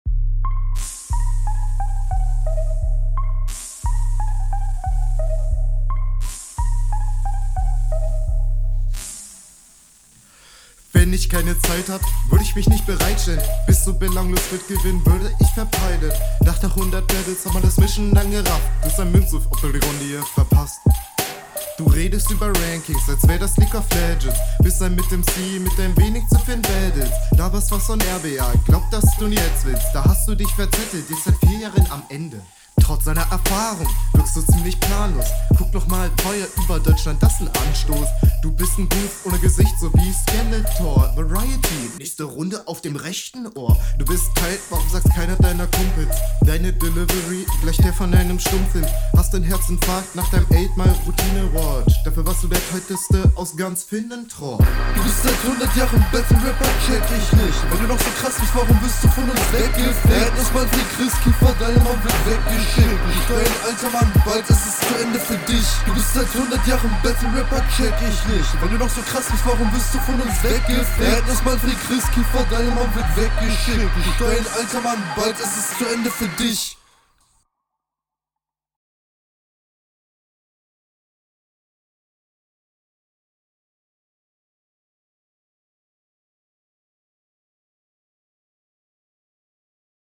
Flow ist leicht vernuschelt und du wirkst etwas unsicher.